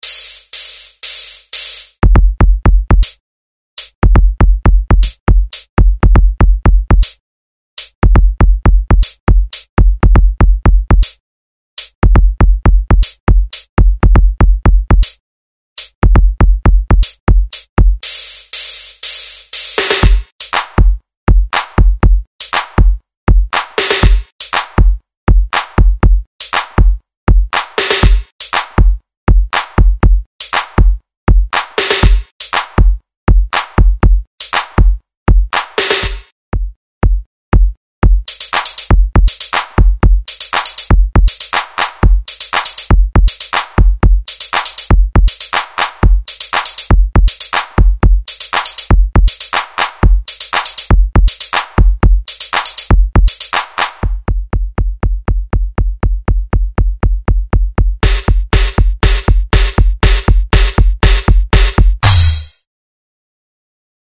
Le Jingle corporel a été inventé par les élèves de l'atelier percussions.
Version rapide
Jingle-120BPM.mp3